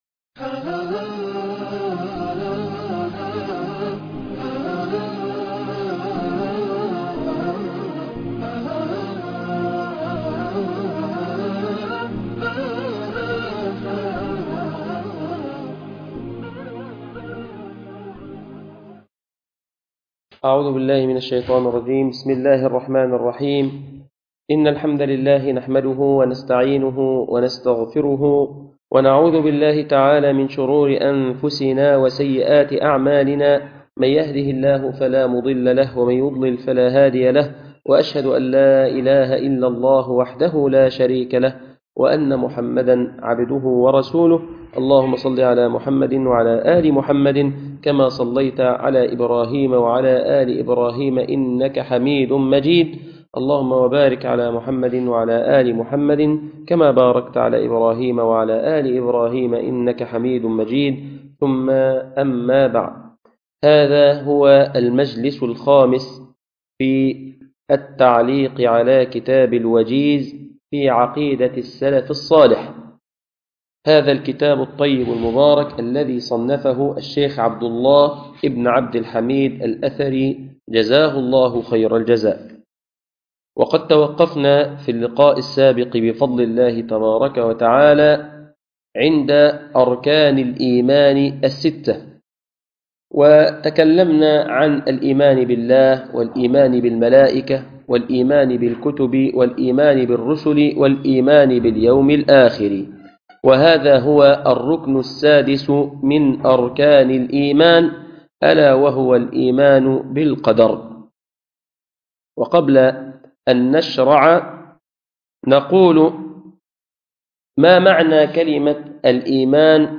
الدرس الرابع فى العقيدة -الإيمان بالقضاء و القدر ج٢- تقريب العلوم للشباب المعاصر